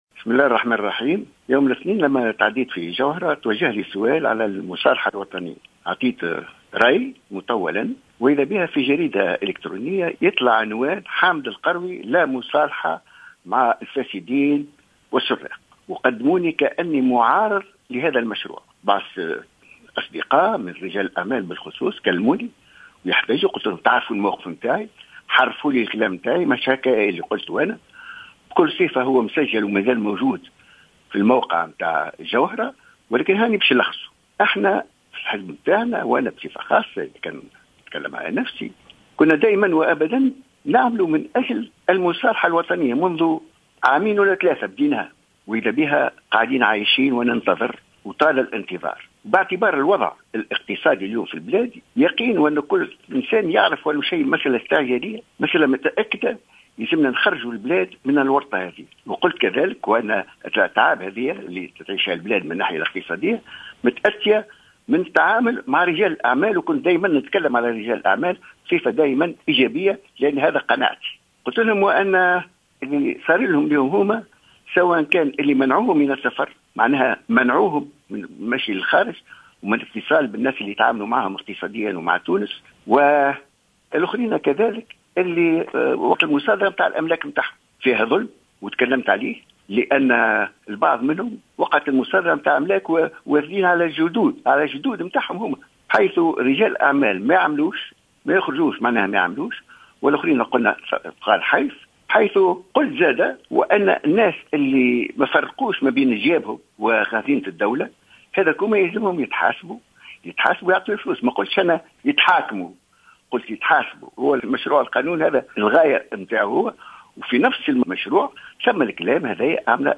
أكد الوزير الأسبق ورئيس الحركة الدستورية حامد القروي في تصريح لجوهرة أف أم اليوم الخميس 13 أوت 2015 أنه مع قانون المصالحة الوطنية نافيا ما راج من أخبار في بعض المواقع الالكترونية حول معارضته لهذا القانون.